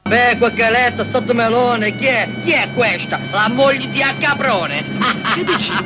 caprone-88569.wav